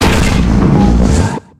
Audio / SE / Cries / GOLURK.ogg